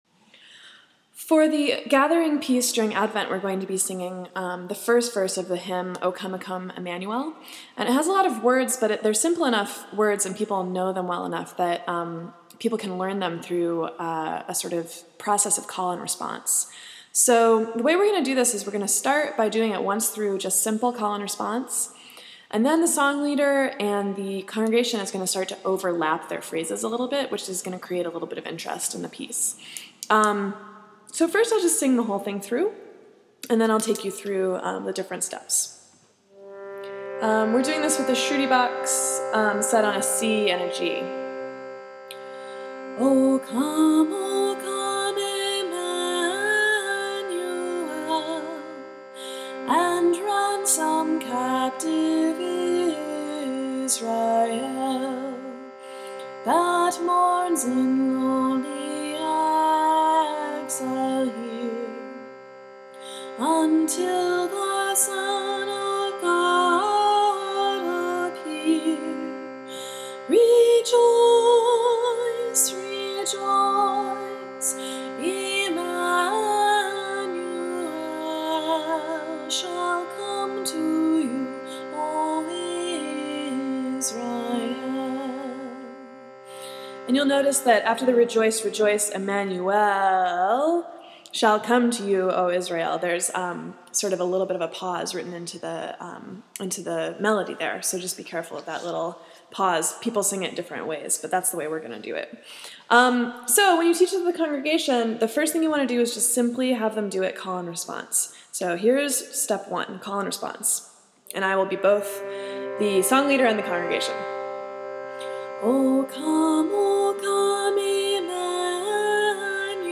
This traditional Advent hymn is our gathering piece in the season of Advent.  We sing it with the song leader leading the congregation in echoing each phrase.
a teaching recording of the melody, but with older lyrics which we no longer use.
O-Come-O-Come-Emmanuel-echo.m4a